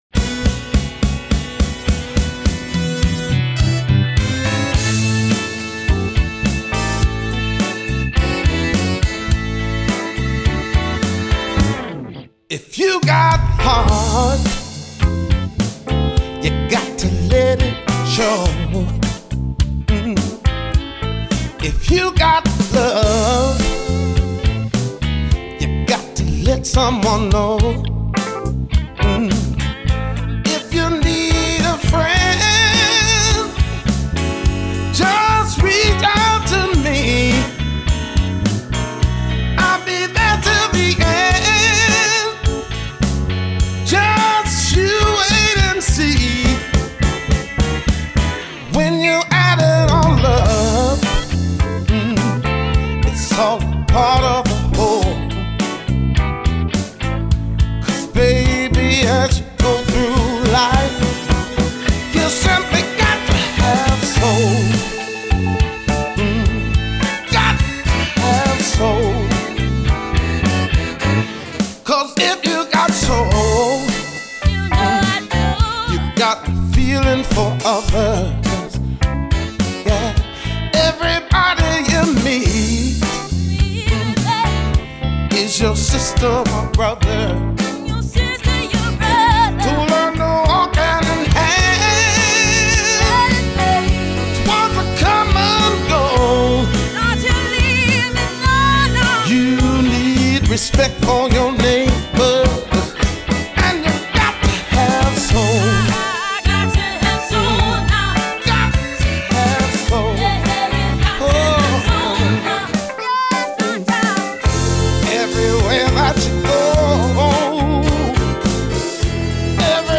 soul/R&B